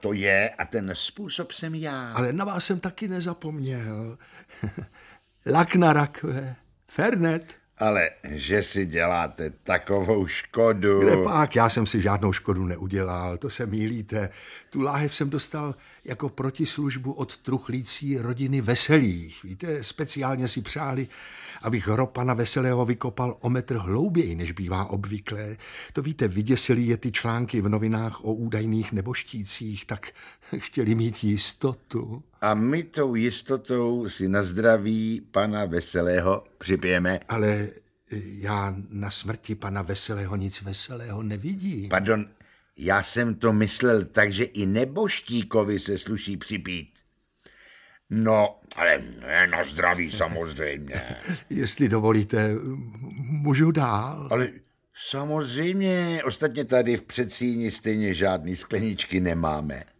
Audiobook
Audiobooks » Short Stories, Humour, Satire & Comedy
Read: Otakar Brousek